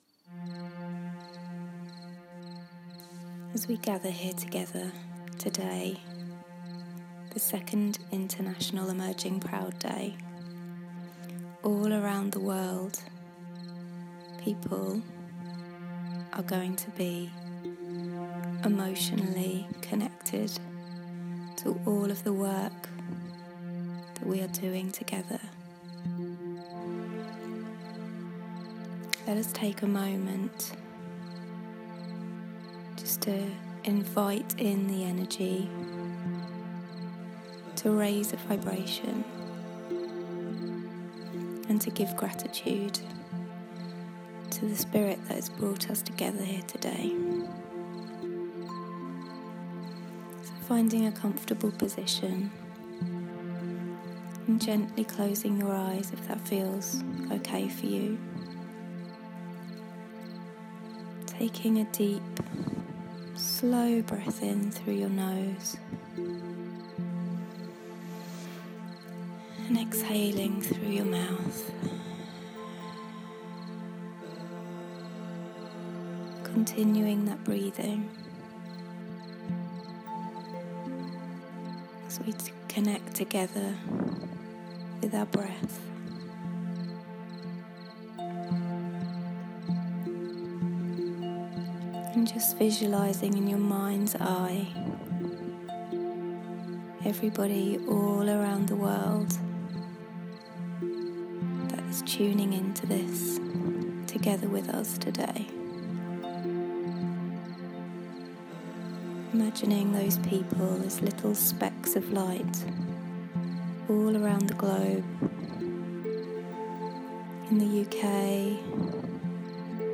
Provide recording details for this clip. The International report for 2018 will take some time to complete; due to very positive reasons which will be shared when we have some more clarity, but for now, I’ll leave you with a beautiful Kirtan from the NYC event…